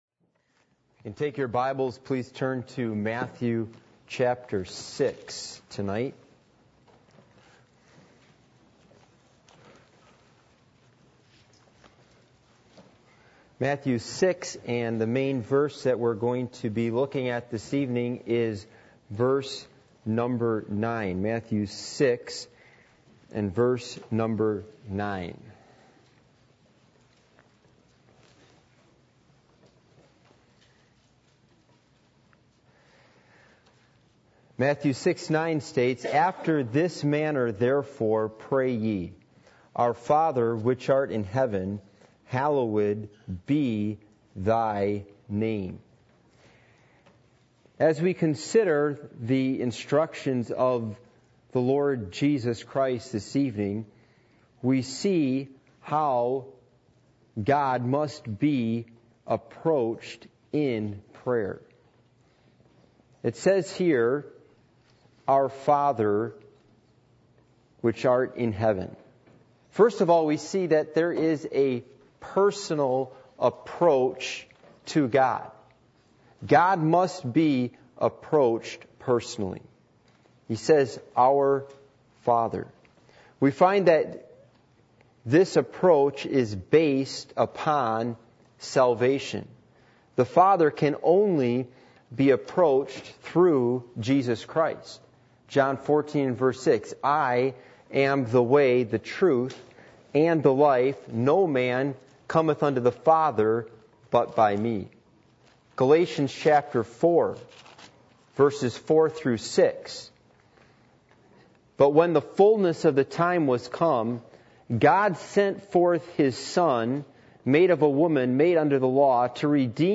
Passage: Matthew 6:8-10 Service Type: Midweek Meeting